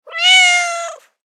دانلود صدای گربه ملوس برای اس ام اس از ساعد نیوز با لینک مستقیم و کیفیت بالا
جلوه های صوتی
برچسب: دانلود آهنگ های افکت صوتی انسان و موجودات زنده دانلود آلبوم صدای گربه از افکت صوتی انسان و موجودات زنده